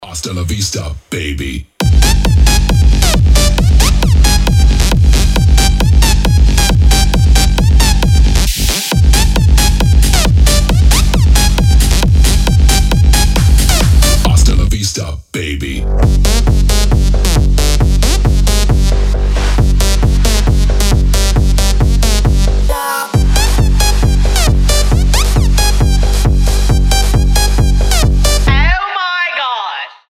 • Качество: 320, Stereo
громкие
мощные
EDM
энергичные
Big Room
electro house
Техно